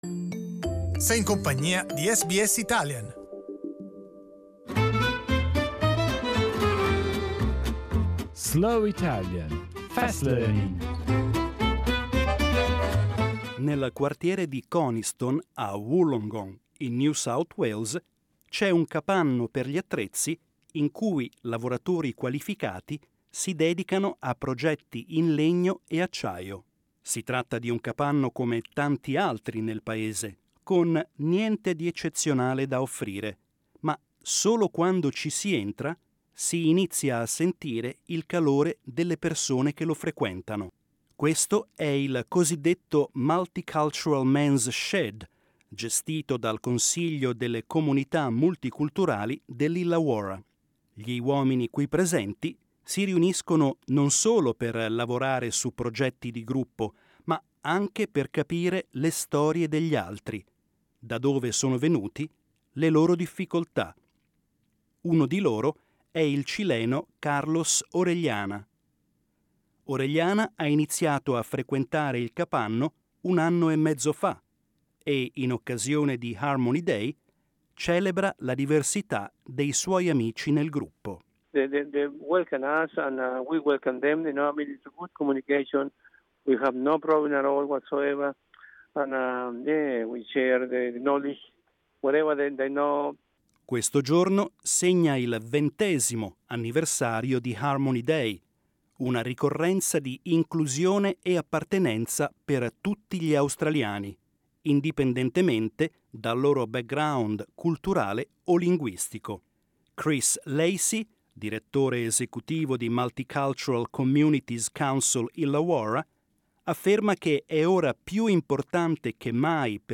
SBS Italian news, with a slower pace.